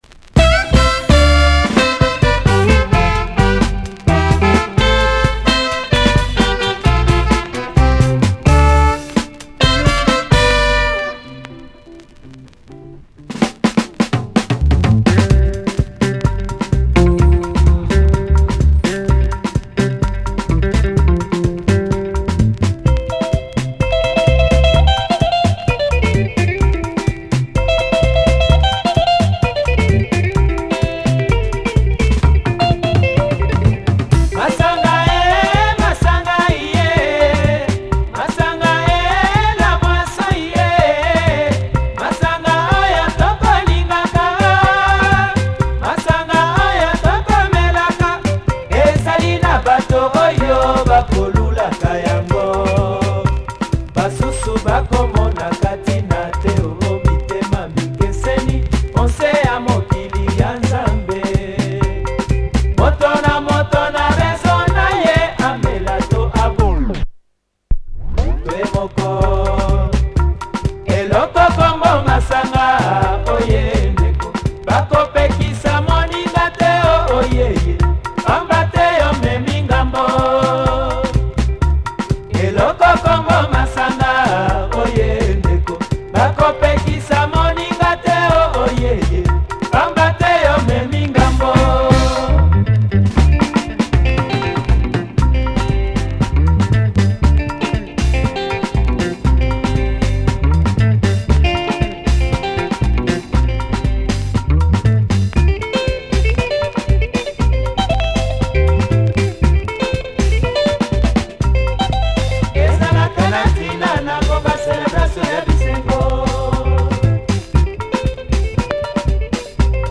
Party Lingala